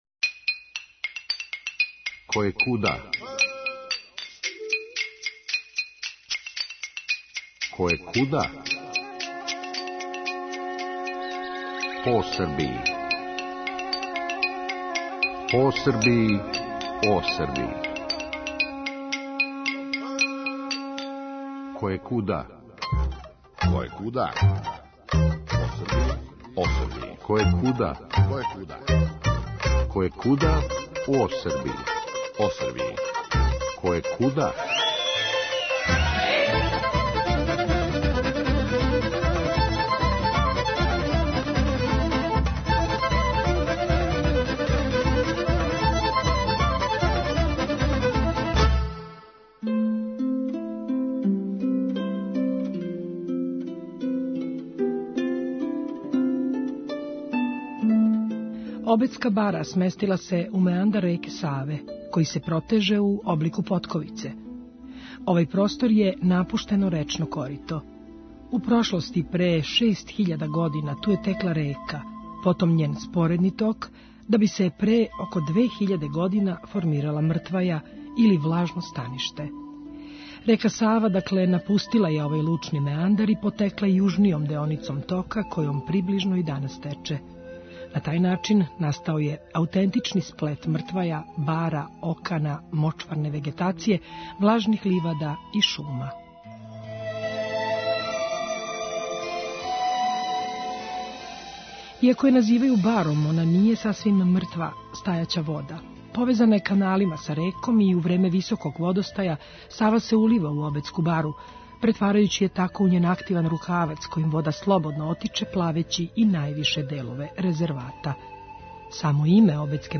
Наша Којекуда прича завирује са обале, кроз трску и шаш међу птице и локвање и назире у даљини тамну и богату шуму која се спаја са водом и са небом. Наша прича је доживљај дуг колико и једно поподне и предвече прохладног пролећног дана.